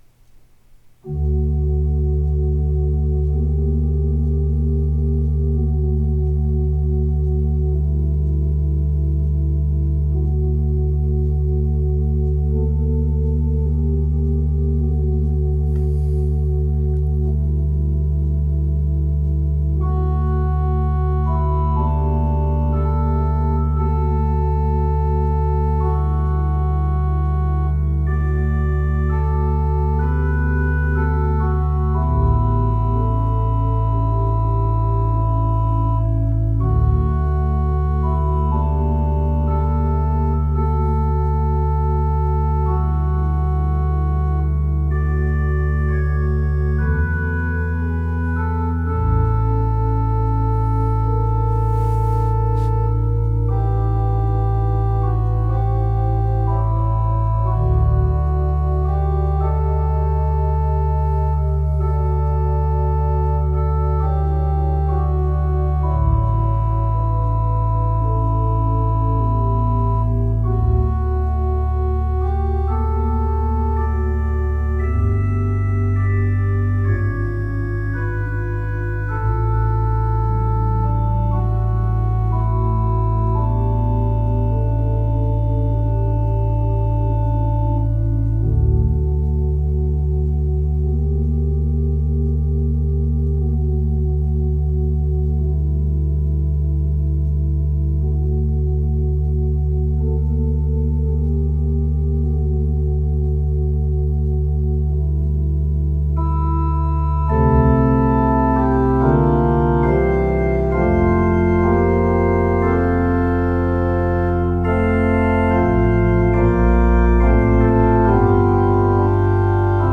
This setting is interesting because the mood is quiet and meditative like a prelude.